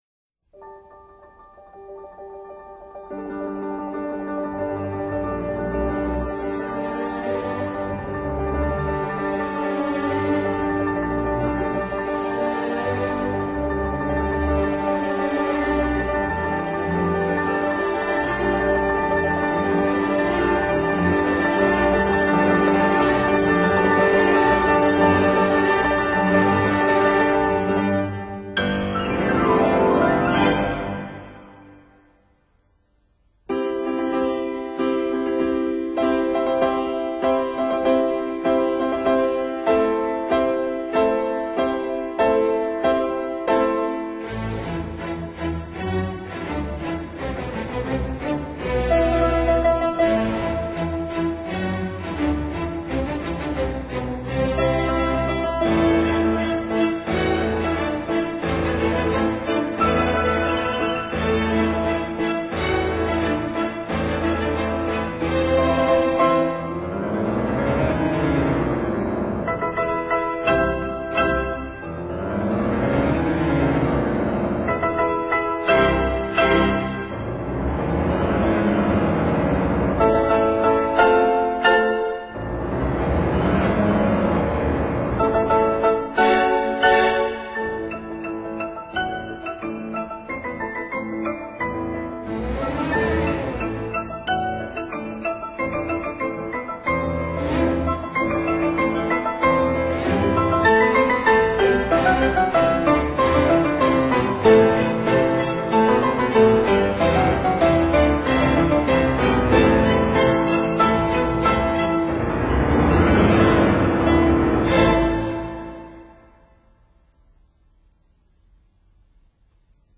乐曲由十三首标题小曲和终曲组成。 （1）《引子与狮王的进行曲》，弦乐与两架钢琴造成从弱到强的音响，引出弦乐组威风凛凛的曲调。
（3）《骡子，跑得飞快的动物》，以两架钢琴形成八度音阶和琶音的快速流动，描绘骡子的奔跑。
（13）《天鹅》，钢琴平静的琶音象碧波荡漾，大提琴舒展的旋律描绘了天鹅昂首缓缓浮游的高雅姿态。